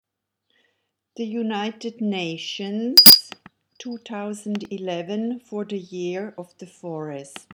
The sound of the bell tells you where a word/expression is missing .